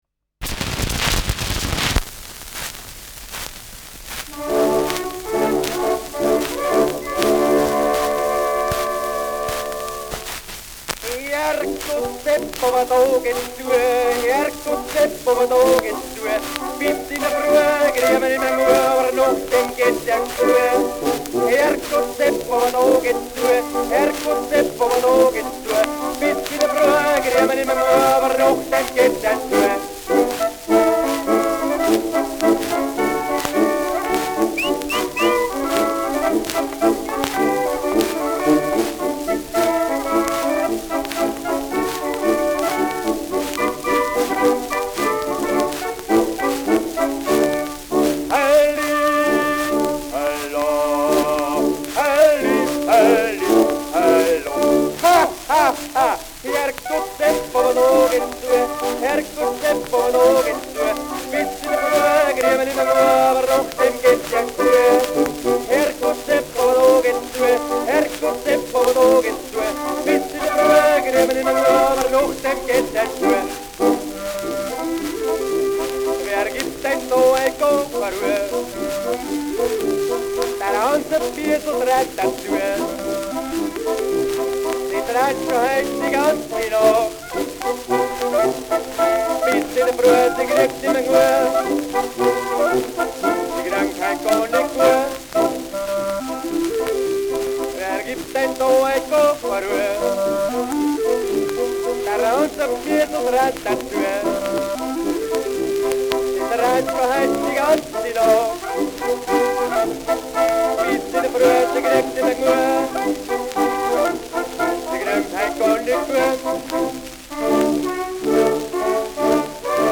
Herrgott, Sepp, aber da geht's zua : Polka mit Gesang [Herrgott, Sepp, aber da geht es zu : Polka mit Gesang]
Schellackplatte
Starkes Nadelgeräusch : Gelegentlich leichtes Knacken
Truderinger, Salzburg (Interpretation)